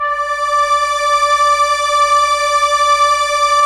Index of /90_sSampleCDs/Roland LCDP09 Keys of the 60s and 70s 1/STR_ARP Strings/STR_ARP Ensemble